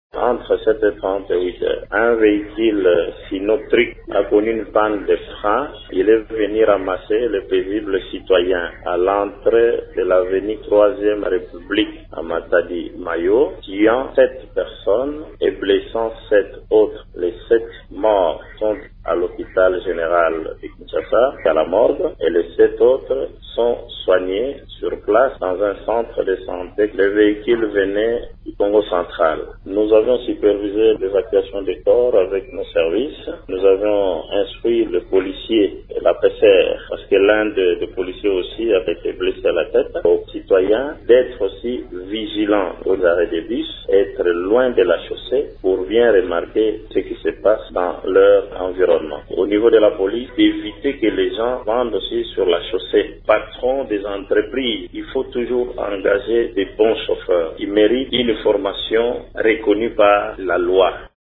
Le bourgmestre de Mont-Ngafula, Plamedie Mbiyavanga, parle d’un problème de freinage, qui serait à la base de ce drame :